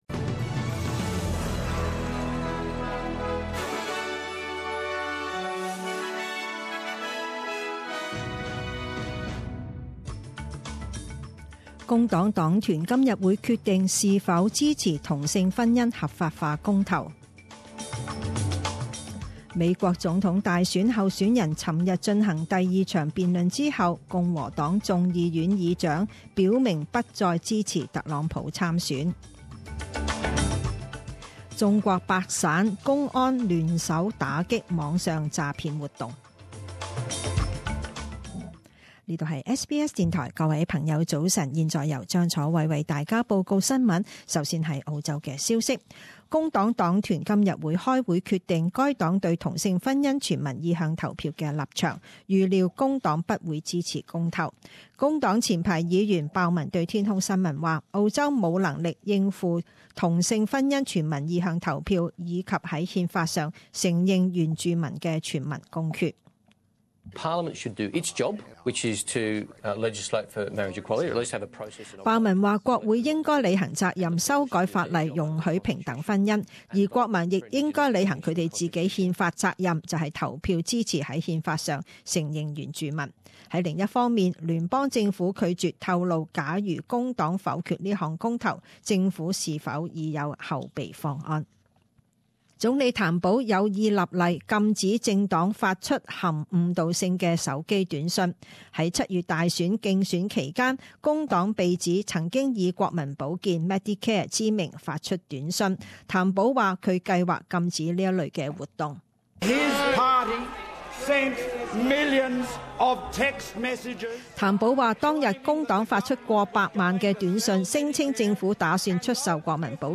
Morning news bulletin